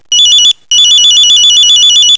Until recently, Chek Lap Kok was an island of small agricultural and fishing villages half a world away from the twittering (
aiff 84k, Real Audio 2 seconds) mobile phones of Hong Kong.
nokia.aif